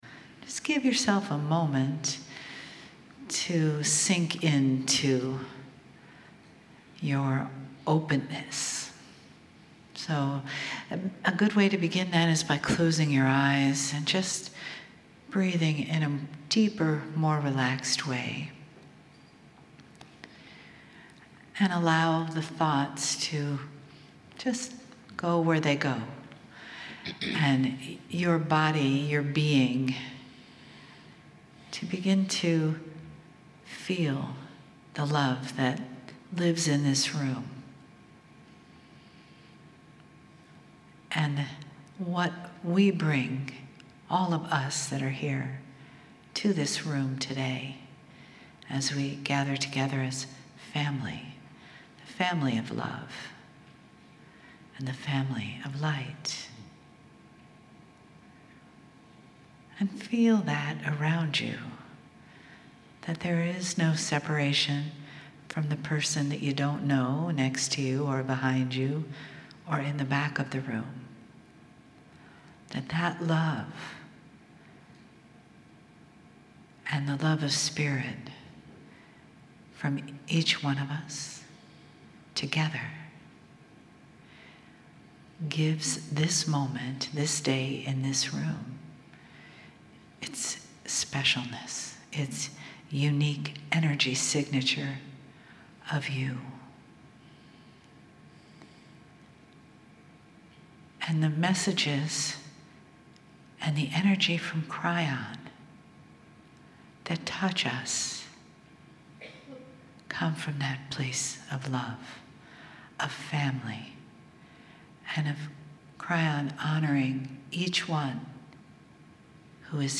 Seattle, Washington Saturday & Sunday - December 9, 10, 2017
Mini - Channelling - SAT